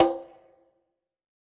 ECONGA R.wav